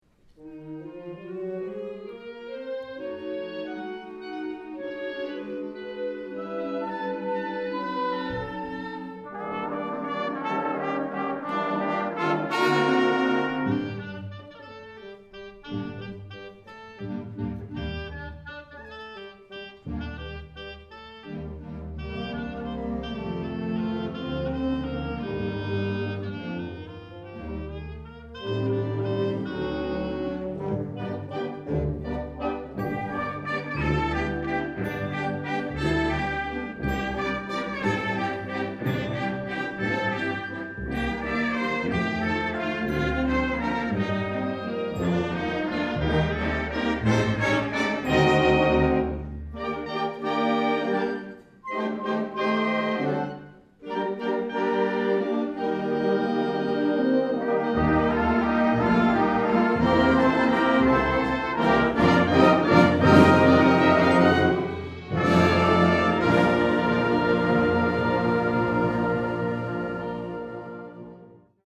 Below are music excerpts from some of our concerts.
2009 Winter Concert
December 20, 2009 - San Marcos High School